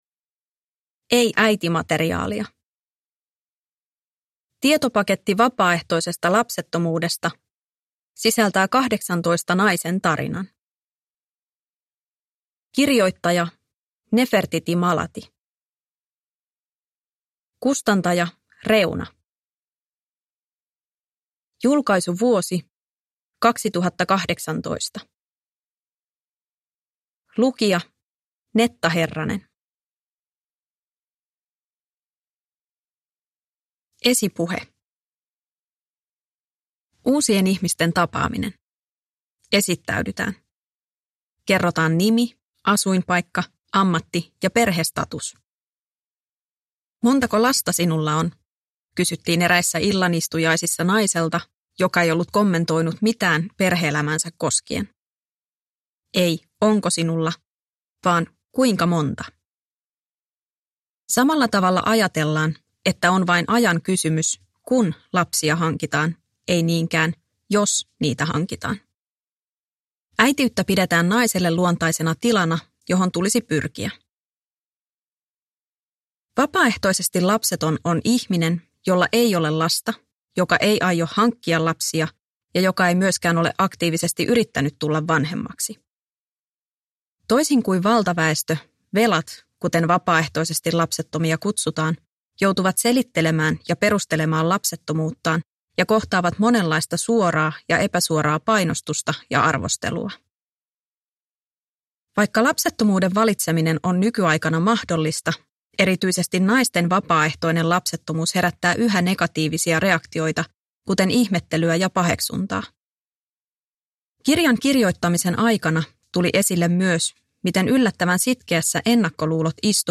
Ei äitimateriaalia – Ljudbok – Laddas ner